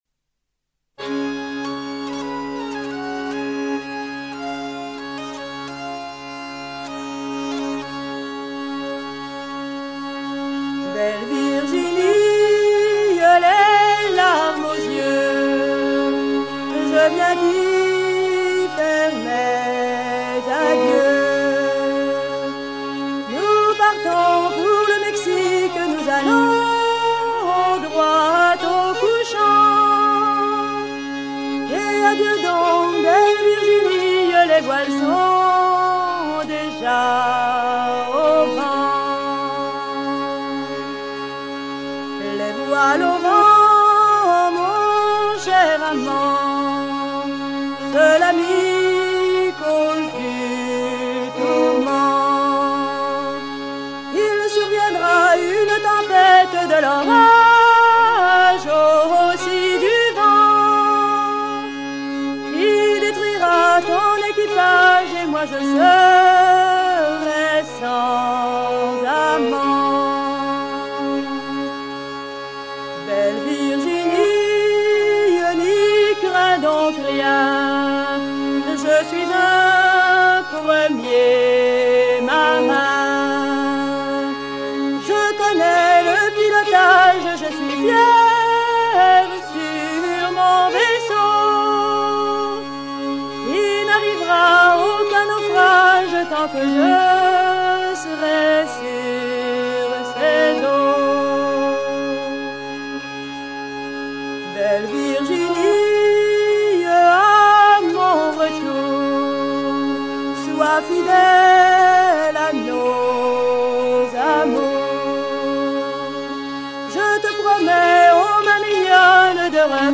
version recueillie à Saint-Romaine, n Beauce, en 1970
Genre strophique
Chants de marins traditionnels